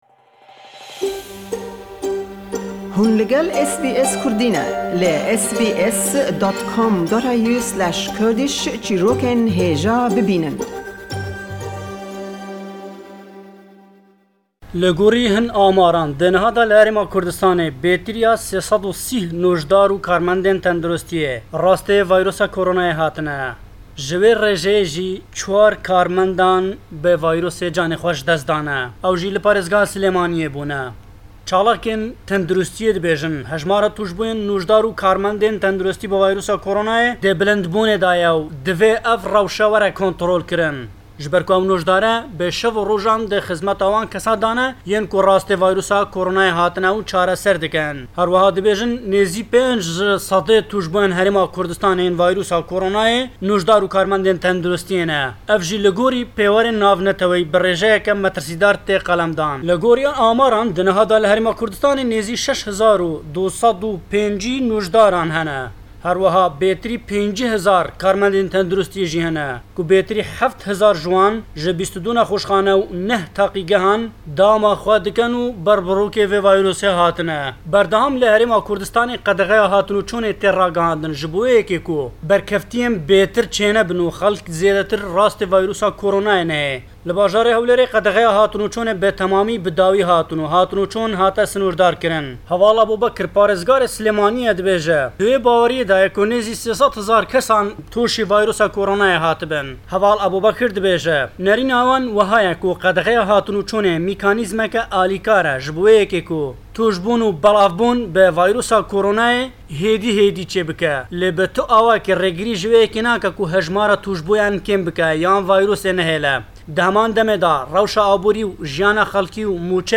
In this week's report from Erbil, the latest news regarding COVID-19 situation in the Kurdistan Region, which seem to be worsening, hence authorities put new lock-down measures in place.